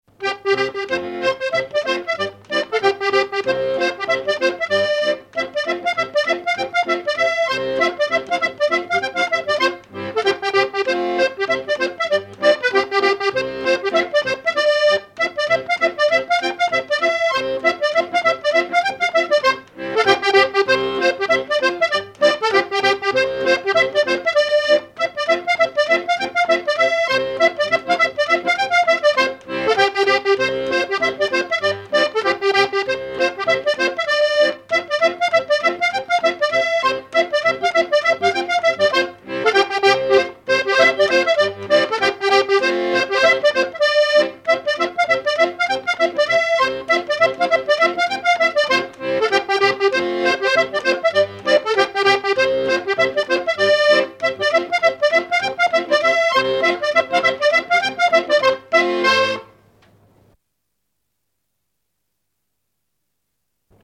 Enregistrement original de l'édition sur disque vinyle
musique pour les assauts de danse et le bal.
accordéon(s), accordéoniste ; musique traditionnelle
danse : paligourdine
Pièce musicale inédite